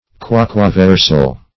Search Result for " quaquaversal" : The Collaborative International Dictionary of English v.0.48: Quaquaversal \Qua`qua*ver"sal\, a. [L. quaqua wheresoever, whithersoever + versus, p. p. of vertere to turn.] 1.